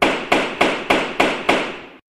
gavel.wav